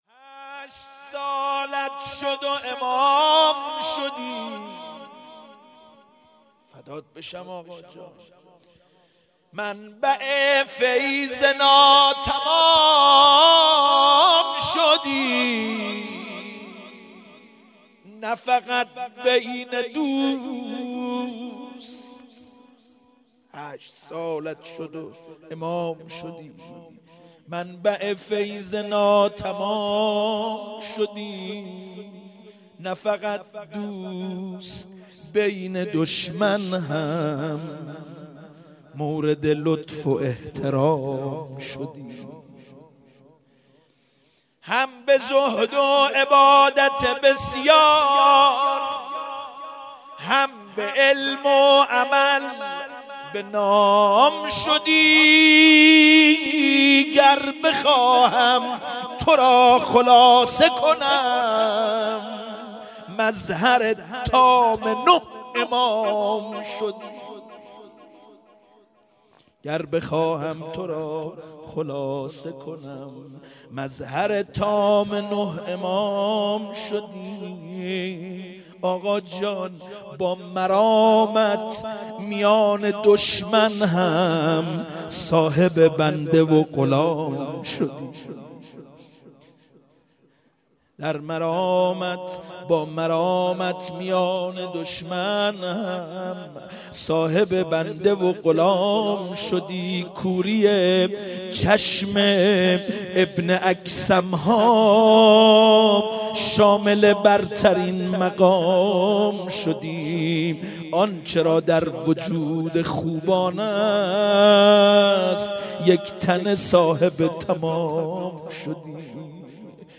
شهادت امام هادی علیه السلام_روضه_هشت سالت شد و امام شدی